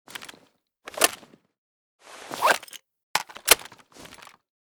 vssk_reload.ogg.bak